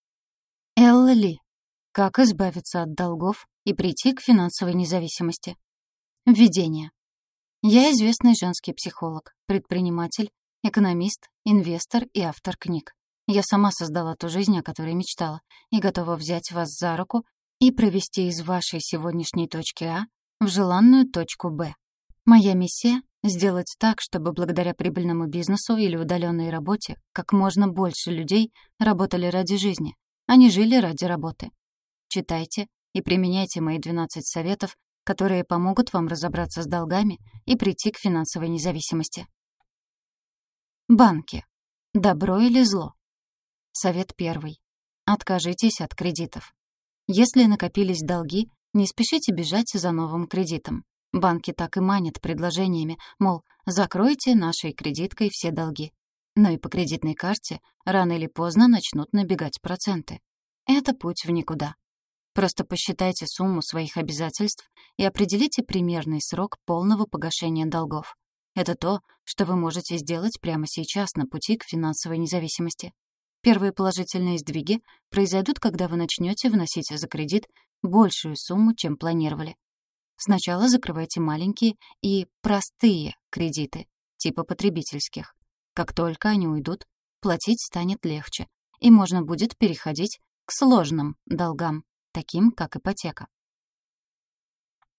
Аудиокнига Как избавиться от долгов и прийти к финансовой независимости | Библиотека аудиокниг